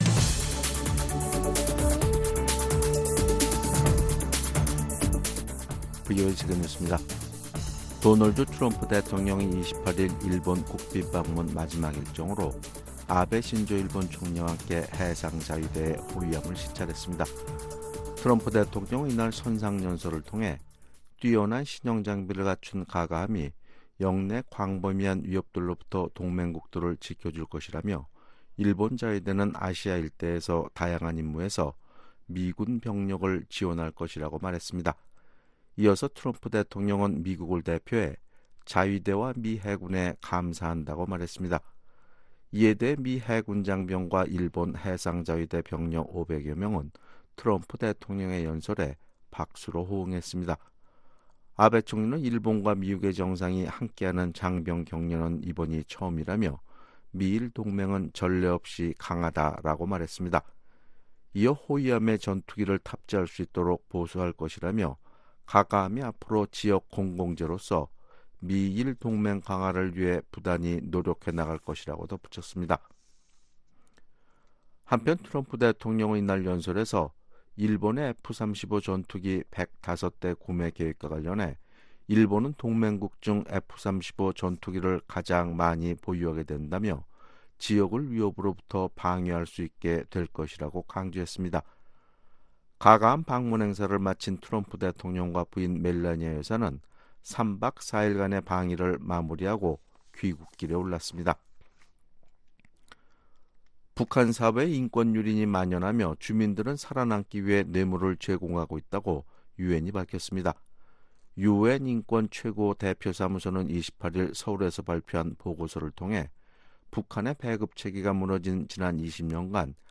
VOA 한국어 아침 뉴스 프로그램 '워싱턴 뉴스 광장' 2019년 5월 29일 방송입니다. 북한 정부가 적합한 생활수준을 누릴 주민들의 권리를 침해한다고 판단할 만한 합당한 근거가 있다고 유엔이 지적했습니다. 북한이 미국의 화물선 몰수에 거세게 반발하는 이유는 선박 압류의 선례가 될지 모른다는 두려움 때문이라고 해상제재 전문가가 밝혔습니다.